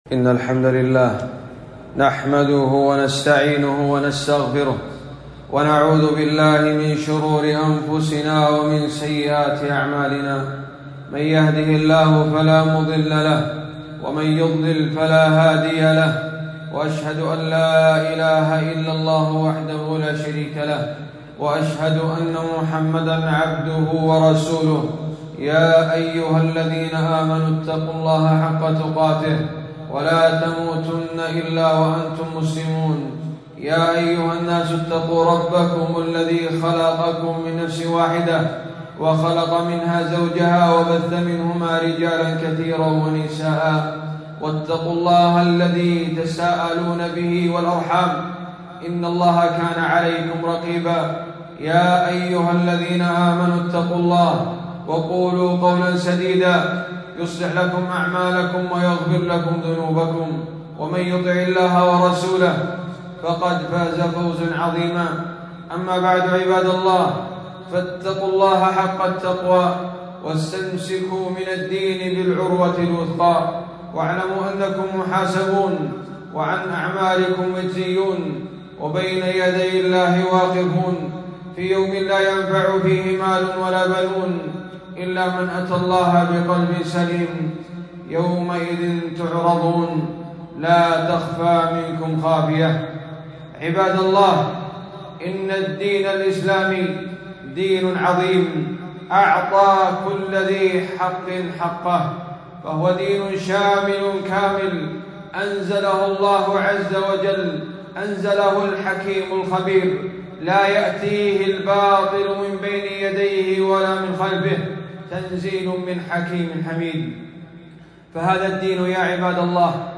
خطبة - حقوق كبار السن في الإسلام